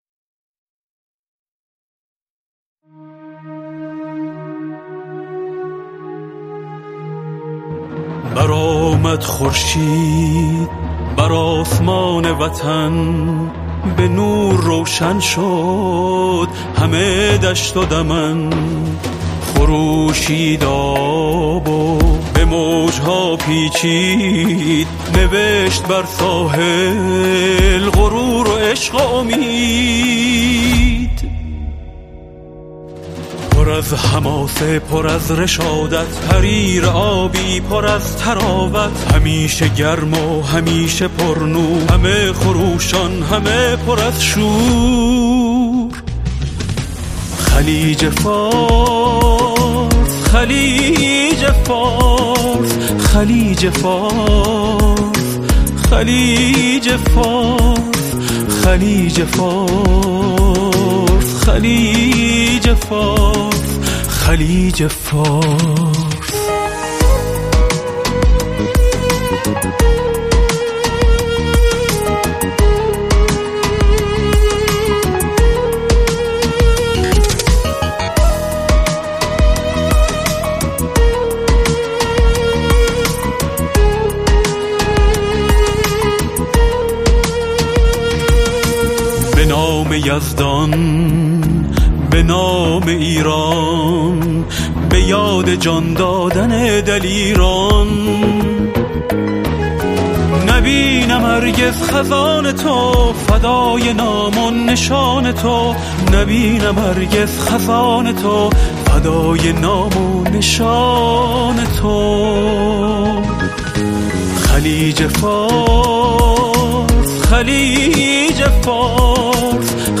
موسیقی ایرانی